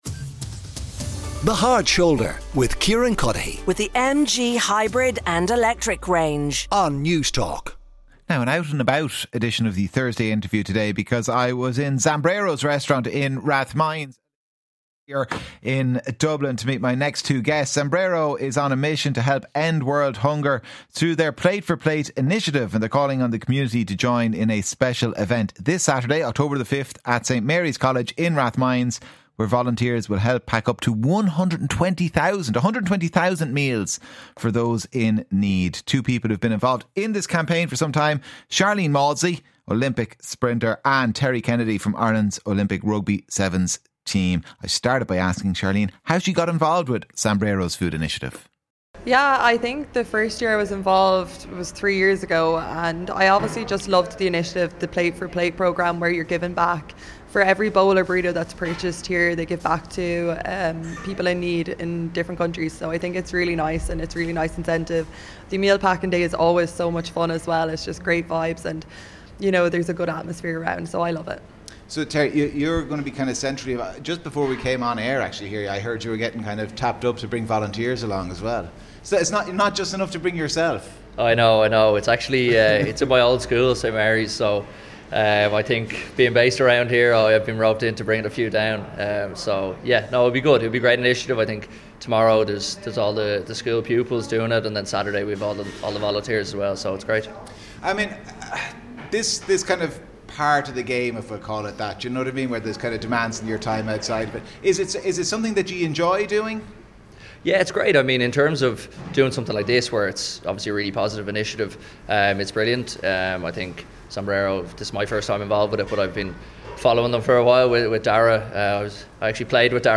two Irish Olympians: Rugby Sevens Terry Kennedy and Olympic Sprinter, Sharlene Mawdsley.